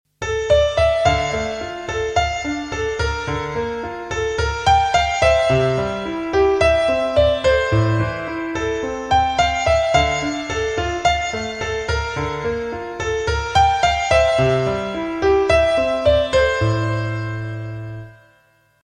• Качество: 320, Stereo
красивые
спокойные
без слов
Cover
инструментальные
пианино
Хорошая игра на фортепьяно